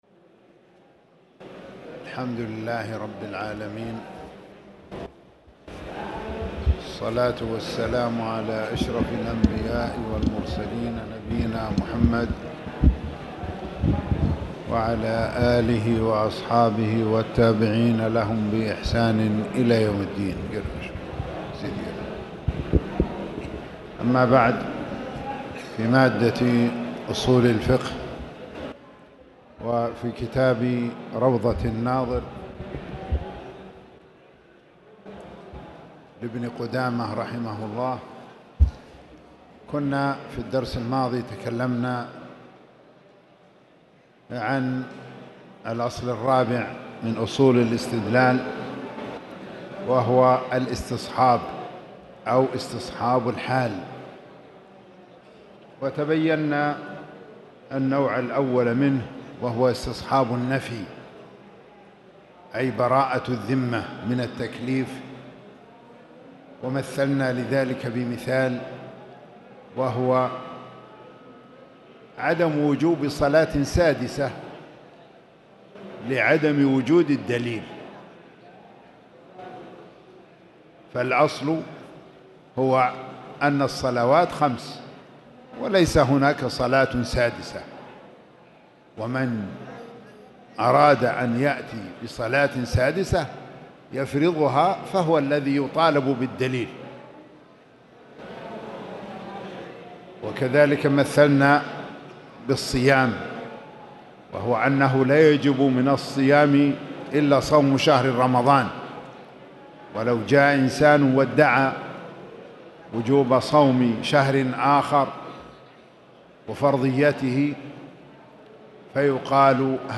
تاريخ النشر ١٢ ربيع الثاني ١٤٣٨ هـ المكان: المسجد الحرام الشيخ